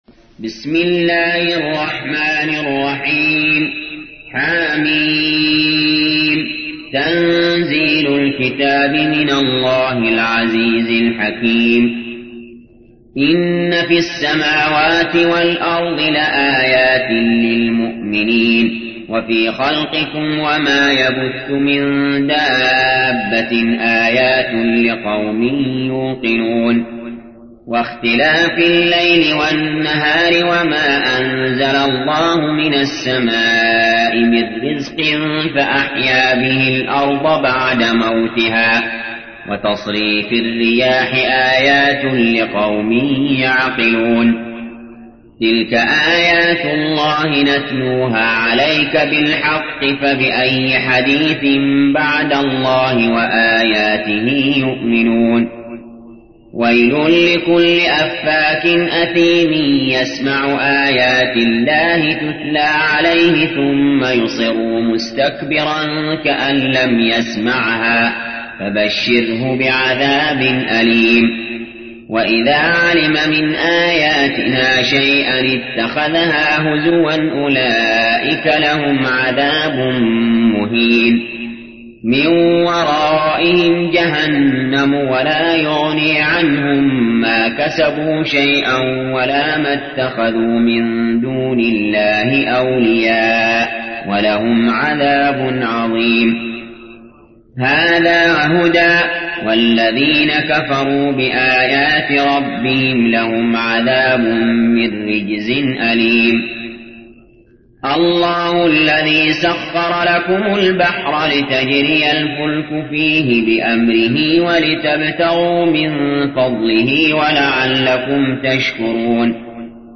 تحميل : 45. سورة الجاثية / القارئ علي جابر / القرآن الكريم / موقع يا حسين